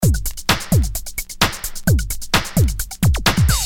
Electro rythm - 130bpm 32